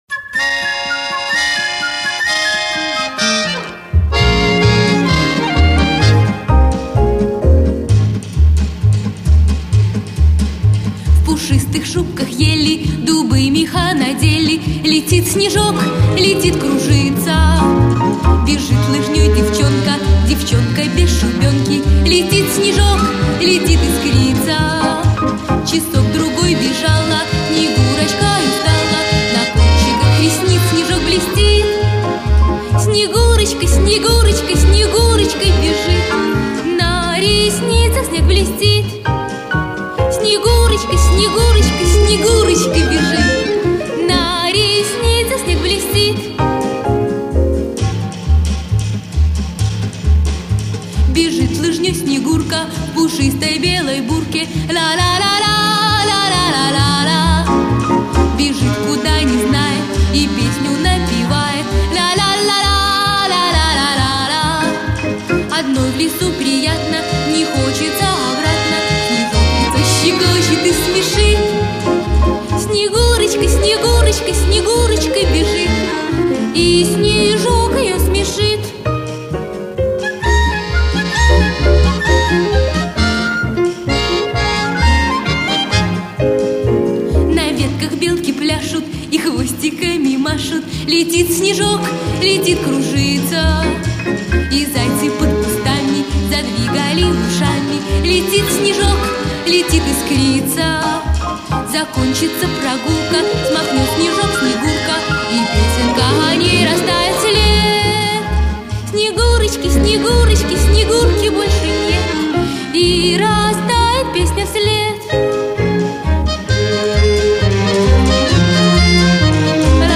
Веселая зимняя песенка